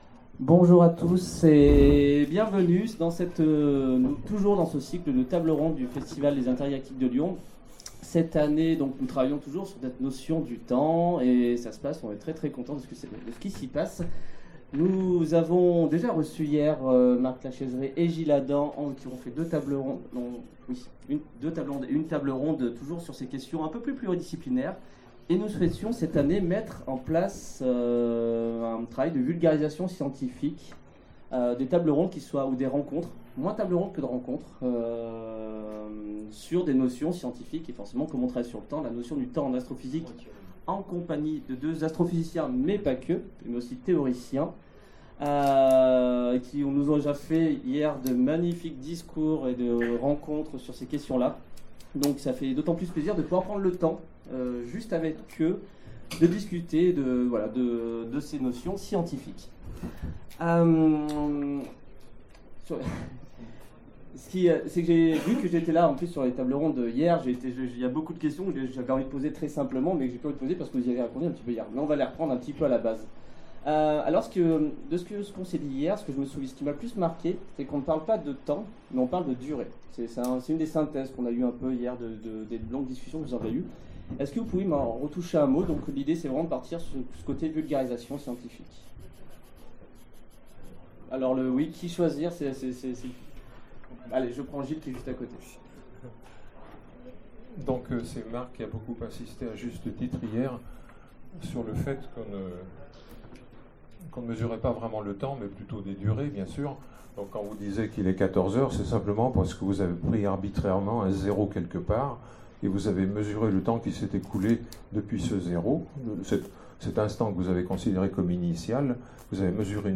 Intergalactiques 2015 : Conférence La notion du temps en astrophysique
Conférence